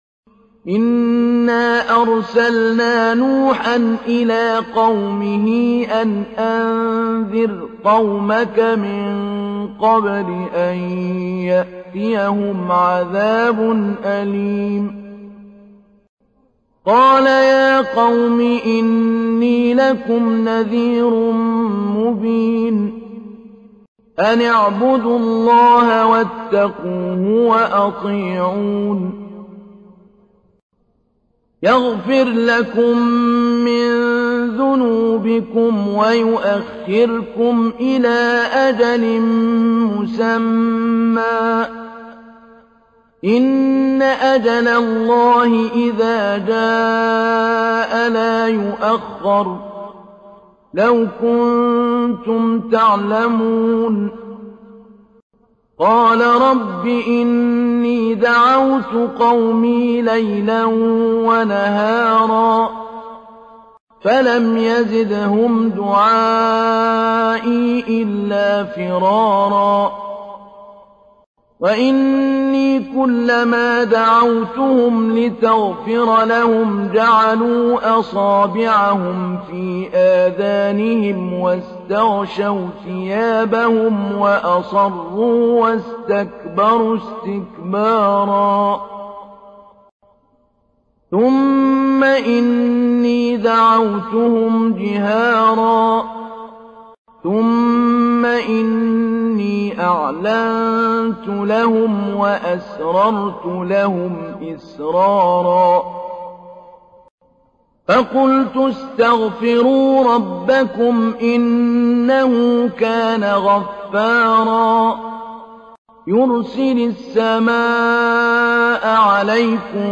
تحميل : 71. سورة نوح / القارئ محمود علي البنا / القرآن الكريم / موقع يا حسين